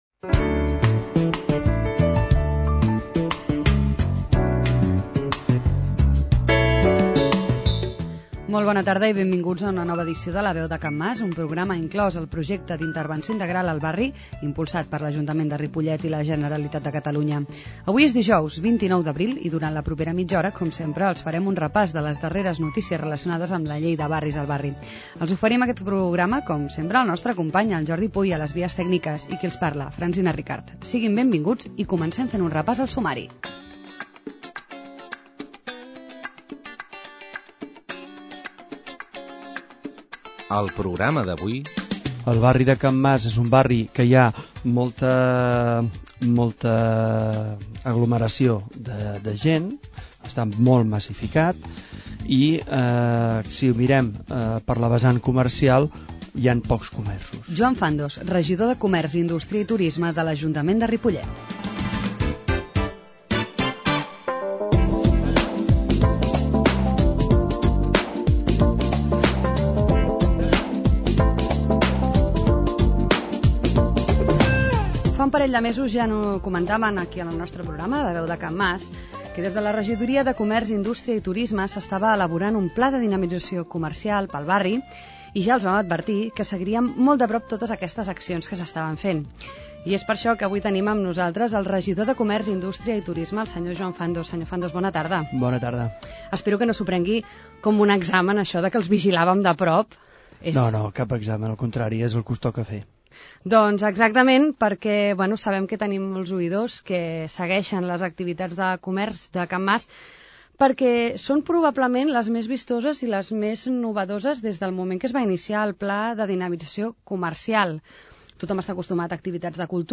Comunicació La veu de Can Mas: programa del 29 d'abril de 2010 -Comunicació- 29/04/2010 A l'edici� del 29 d'abril de La veu de Can Mas, ens ha visitat el regidor de Comer�, Ind�stria i Turisme, Joan Fandos, per parlar sobre les noves activitats del Pla de Dinamitzaci� Comercial que s'est� realitzant al barri.
La Veu de Can Mas �s un programa de r�dio incl�s en el Projecte d'Intervenci� Integral al barri de Can Mas, que s'emet el darrer dijous de mes, de 19 a 19.30 hores i en redifusi� diumenge a les 11 del mat�.